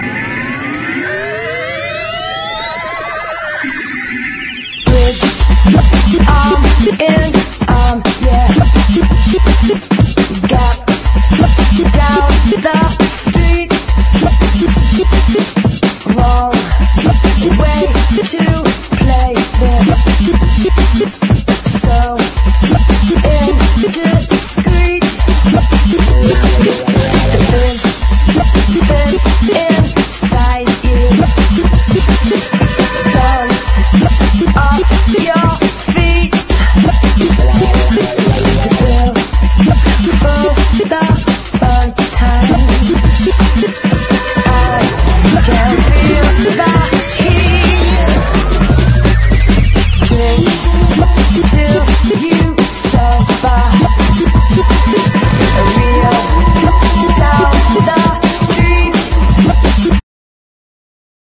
BREAKBEATS/HOUSE / GROUND BEAT / UK SOUL
硬派なハウス・ナンバーからチャラいユーロ・ポップ、ロック畑のダンス・チューンまで縦横無尽に網羅！